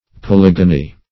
polygony - definition of polygony - synonyms, pronunciation, spelling from Free Dictionary Search Result for " polygony" : The Collaborative International Dictionary of English v.0.48: Polygony \Po*lyg"o*ny\, n. (Bot.) Any plant of the genus Polygonum.